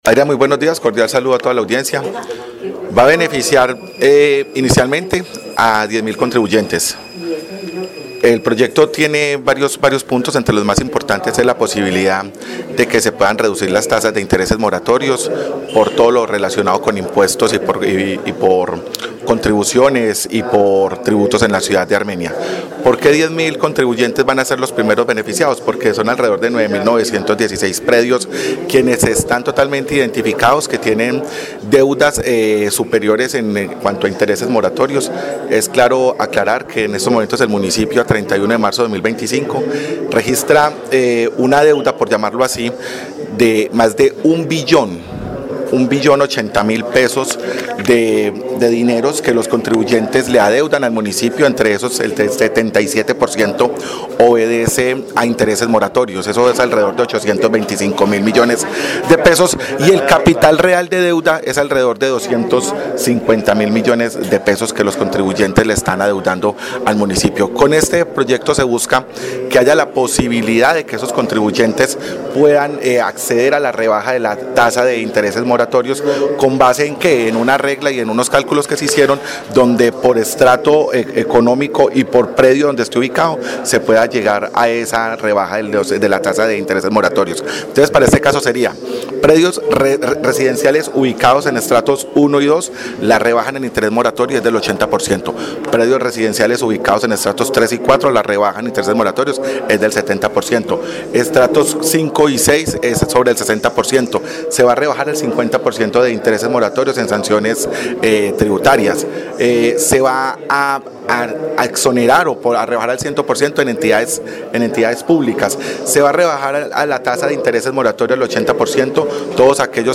Jhonny Vargas, concejal de Armenia